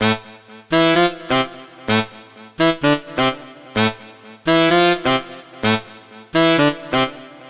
萨克斯风3
描述：巴尔干风格...如果你想使用这个，请告诉我。
Tag: 128 bpm House Loops Brass Loops 1.26 MB wav Key : Unknown